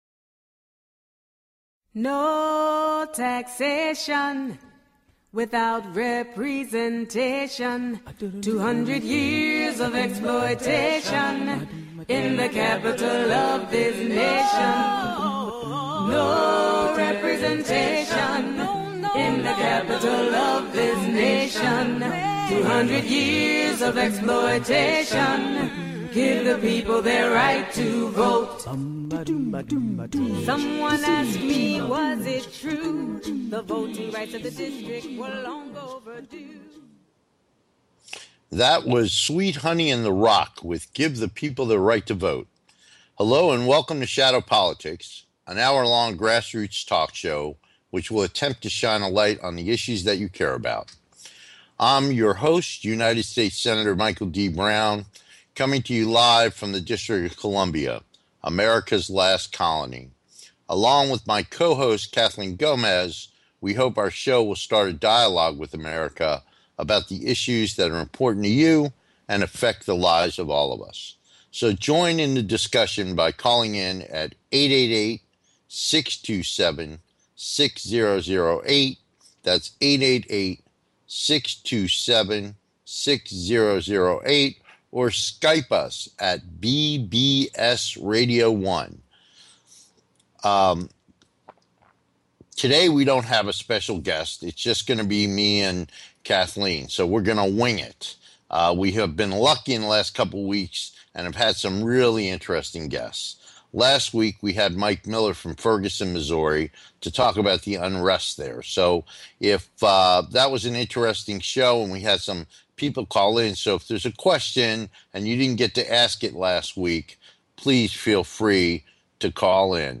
Shadow Politics is a grass roots talk show giving a voice to the voiceless. For more than 200 years the people of the Nation's Capital have ironically been excluded from the national political conversation.
We look forward to having you be part of the discussion so call in and join the conversation.